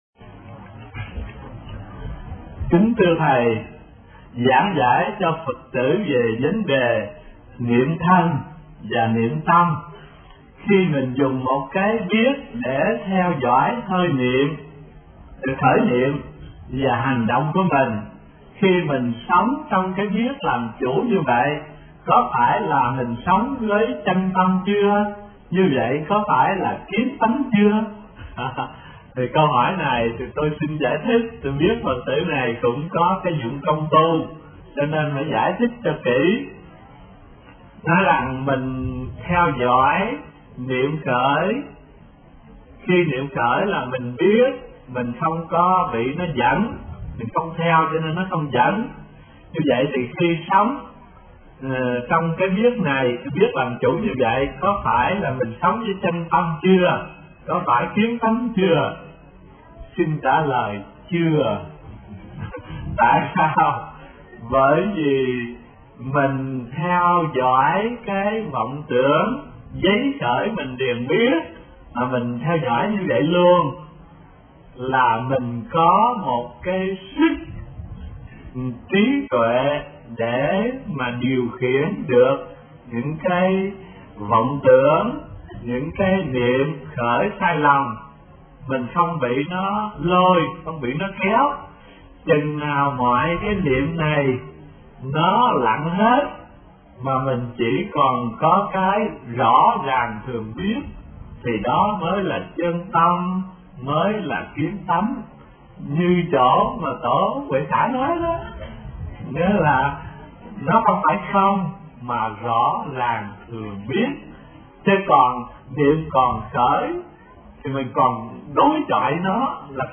Niệm Thân Và Niệm Tâm Như Thế Nào – Tham vấn HT Thanh Từ 64